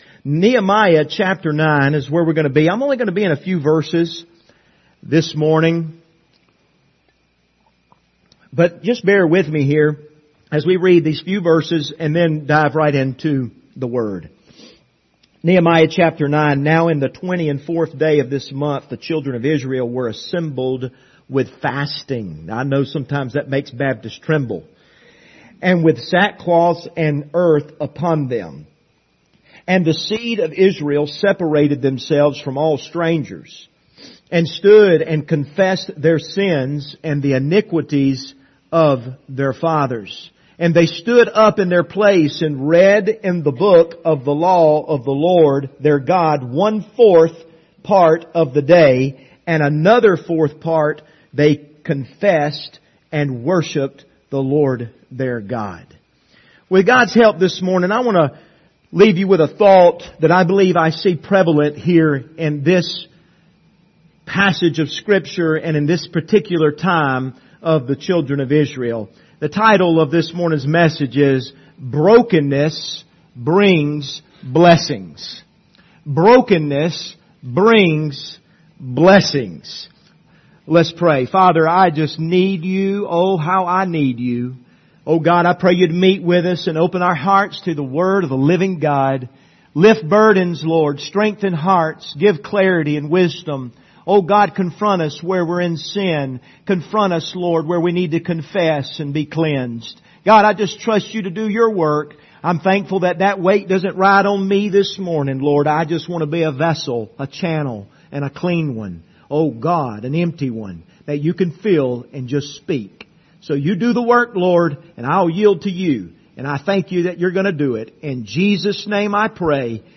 Passage: Nehemiah 9:1-3 Service Type: Sunday Morning